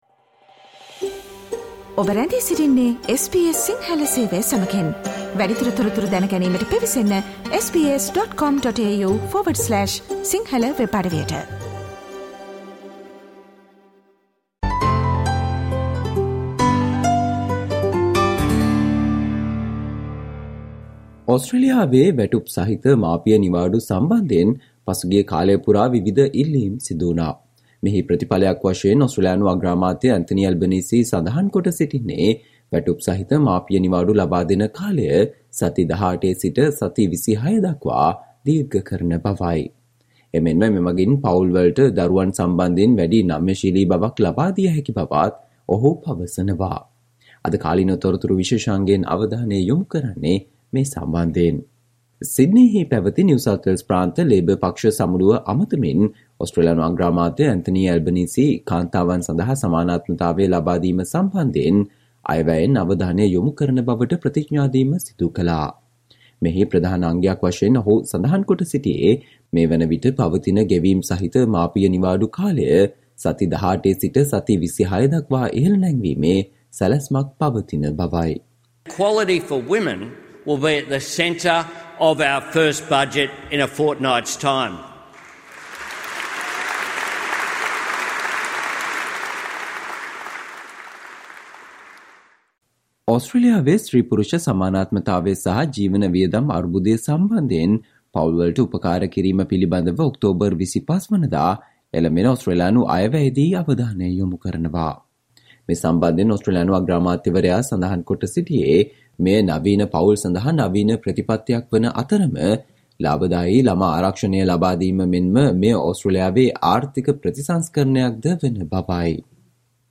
Today - 17 October, SBS Sinhala Radio current Affair Feature on Government announces boost to paid parental leave